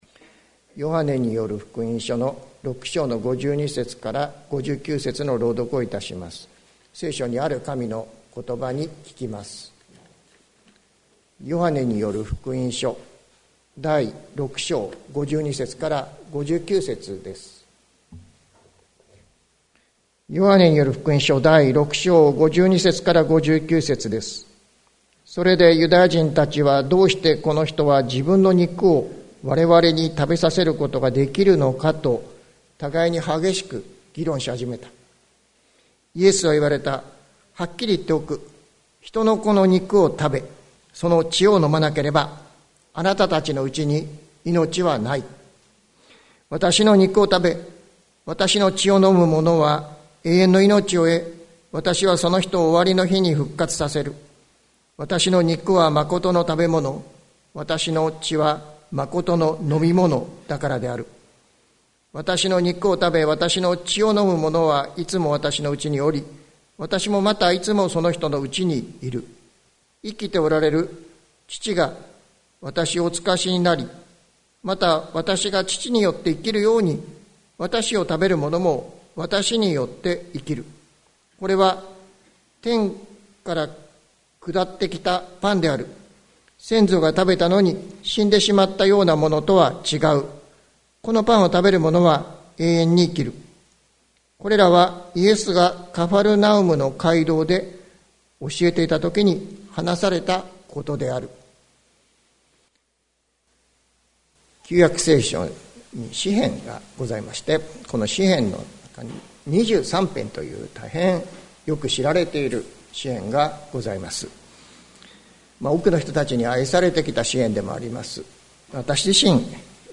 2022年05月22日朝の礼拝「まことの食べ物、まことの飲み物」関キリスト教会
関キリスト教会。説教アーカイブ。